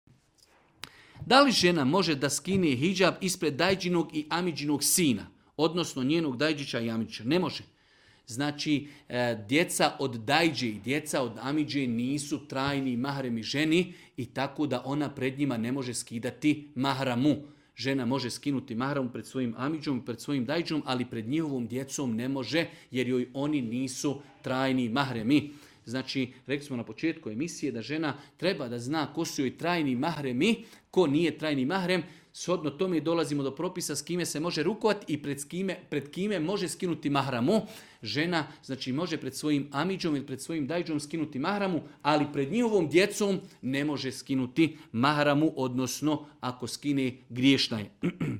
Audio isječak odgovora Tvoj web preglednik ne podrzava ovaj fajl, koristi google chrome.